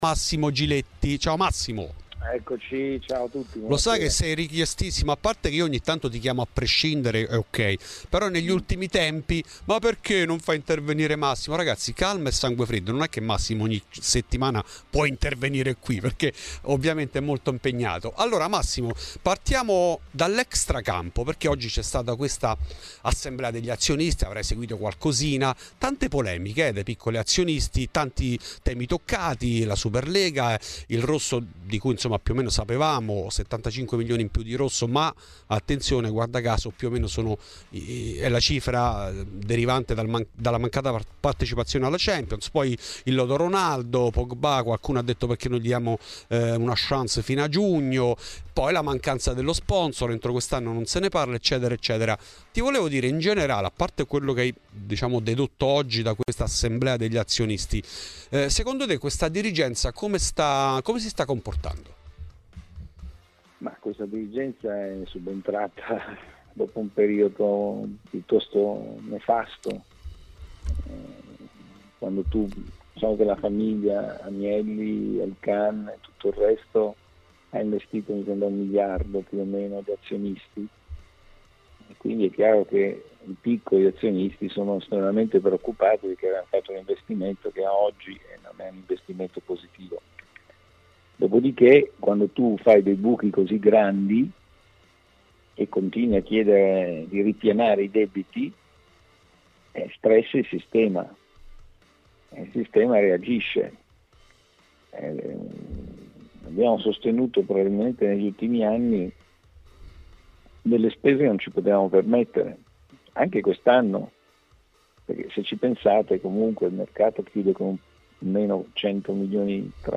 In ESCLUSIVA a Fuori di Juve Massimo Giletti.